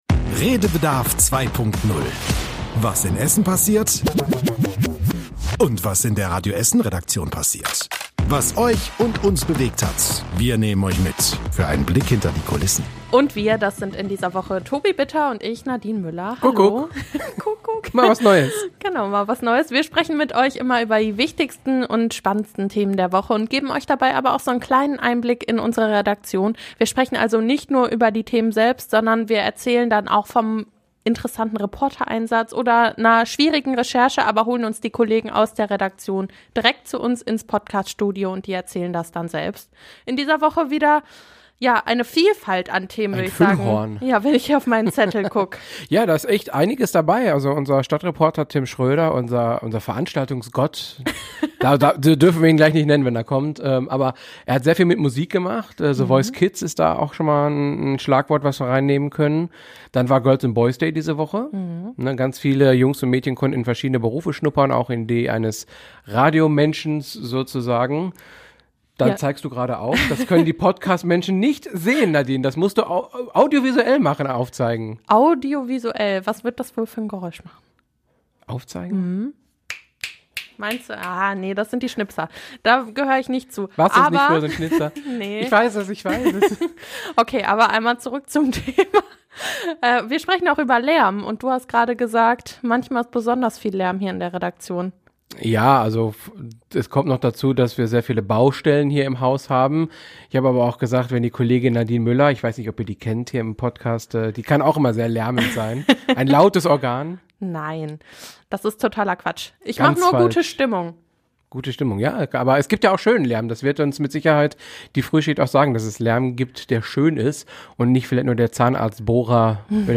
Es wird laut, also dreht nicht voll auf. In der Radio Essen-Frühschicht gab es Pfiffe und ein kleines Comeback.